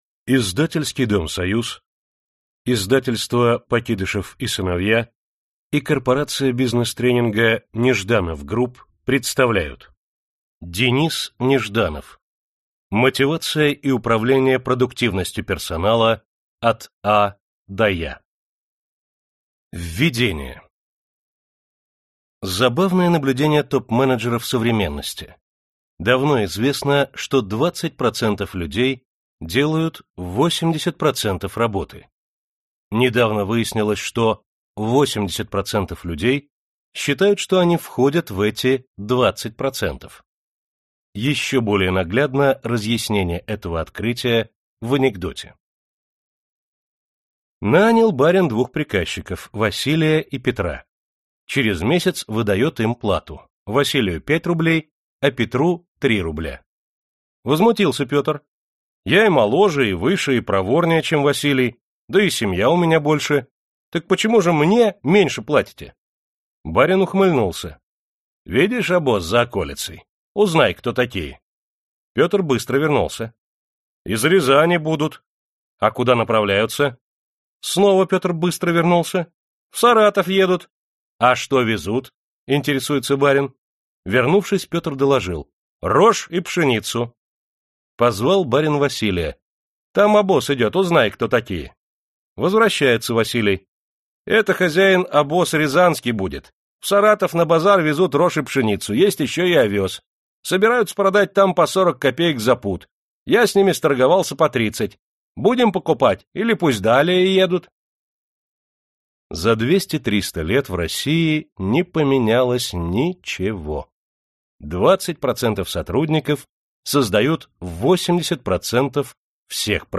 Аудиокнига Мотивация и управление продуктивностью персонала от «А» до «Я» | Библиотека аудиокниг